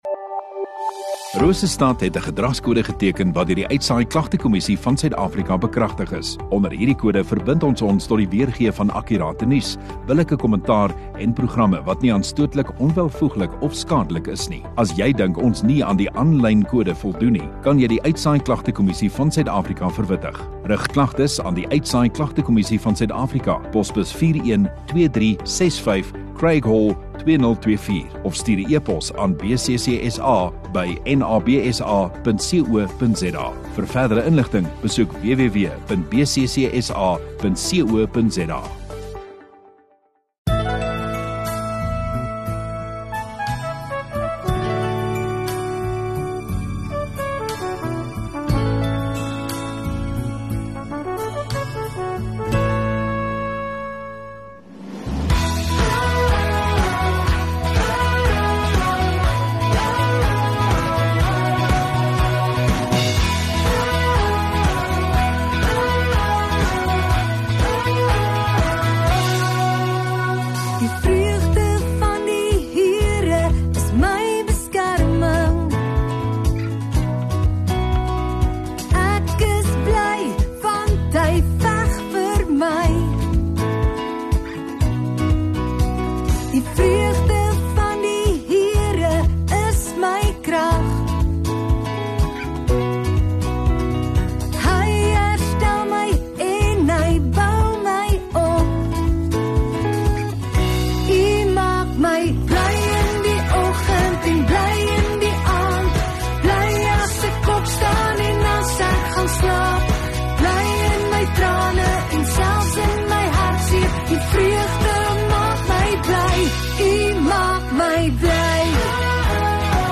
23 Nov Saterdag Oggenddiens